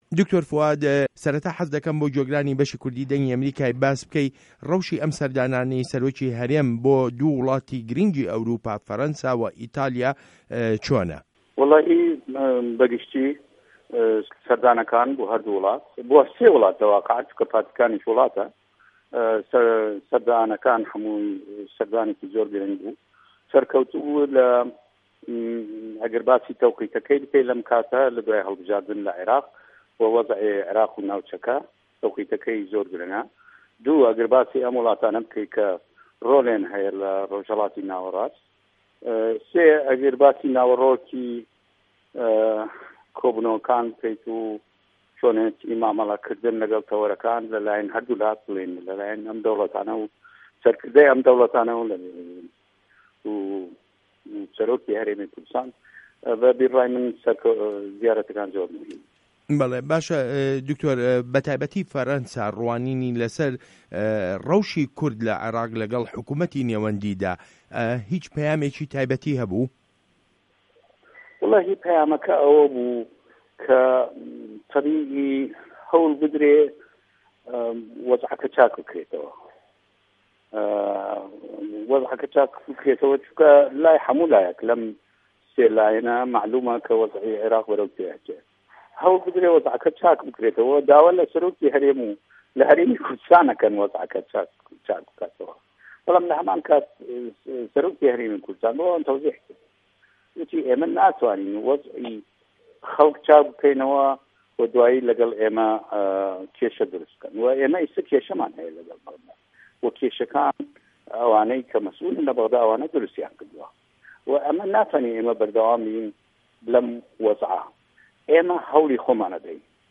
وتووێژ له‌گه‌ڵ دکتۆر فوئاد حوسه‌ین